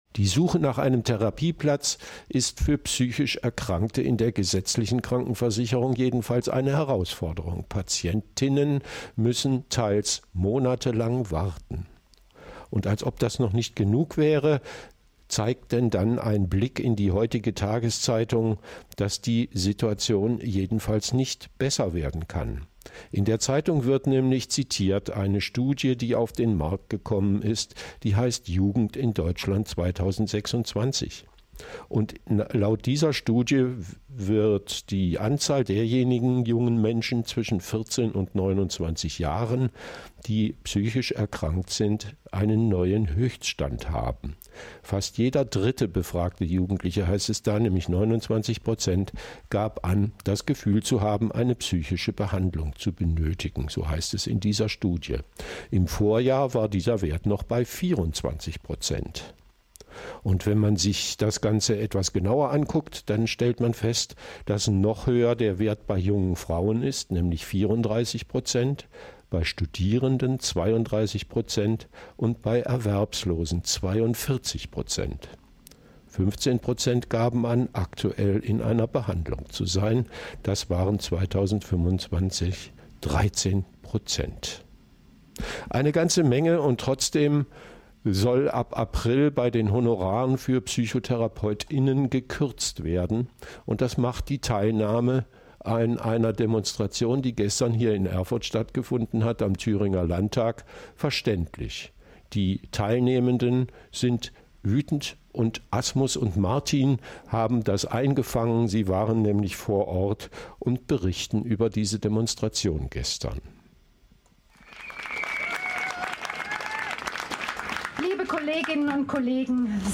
Bericht von der Demo Mehr Infos